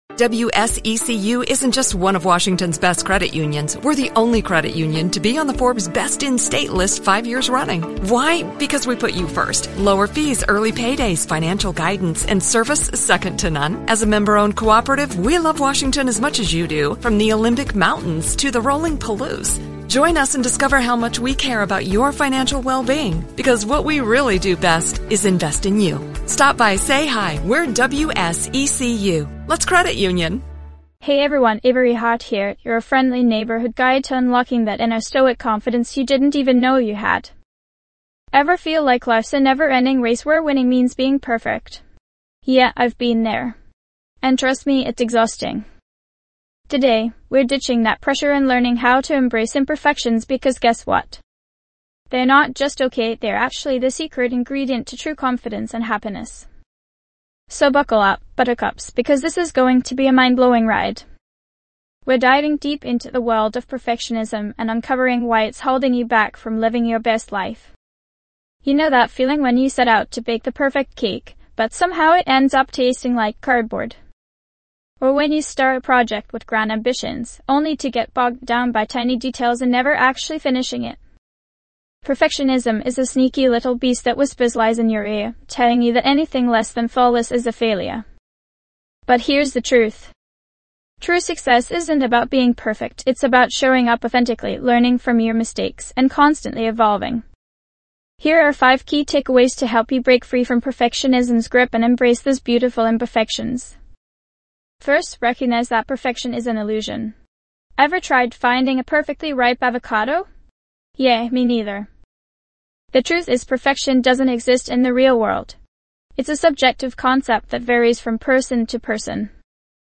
Podcast Category:. Personal Development, Self-help, Mental Health, Mindfulness, Inspirational Talks
This podcast is created with the help of advanced AI to deliver thoughtful affirmations and positive messages just for you.